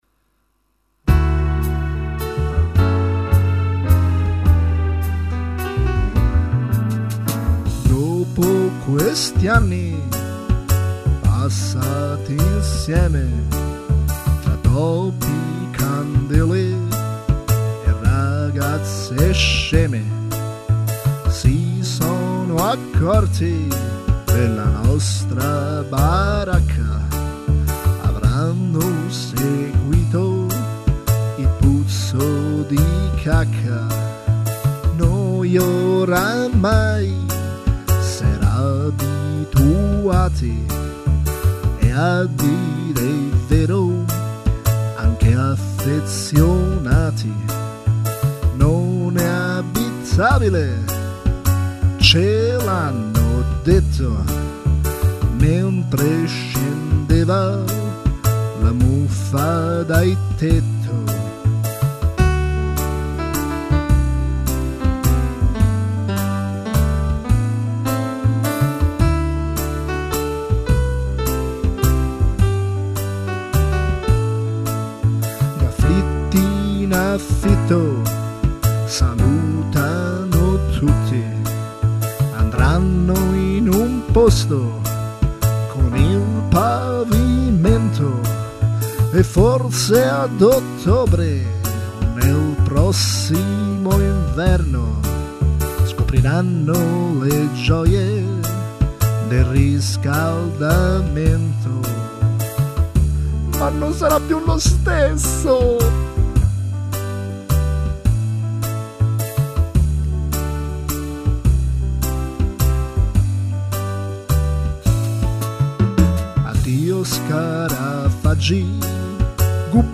La canzone non e' male, vagamente nostalgica come chiesto.